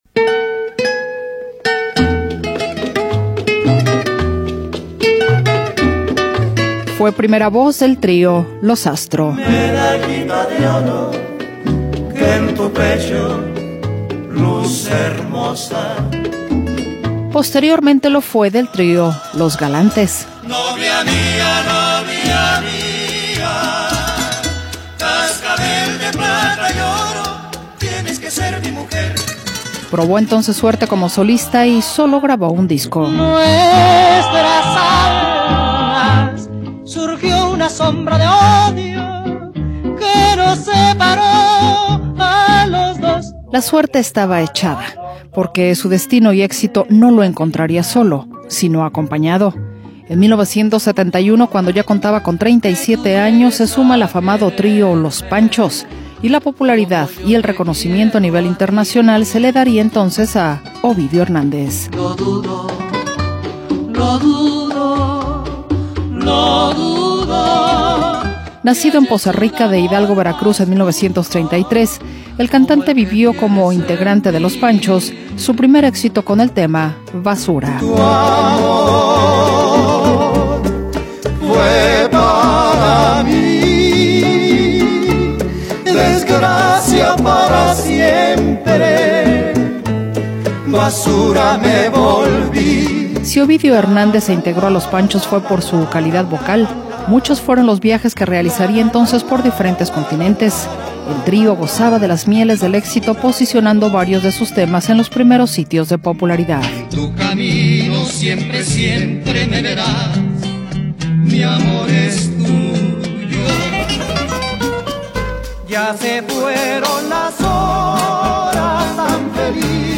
Su estilo amable, romántico y bolerístico contribuyó a mantener la tradición de los tríos latinoamericanos en una época de cambio musical.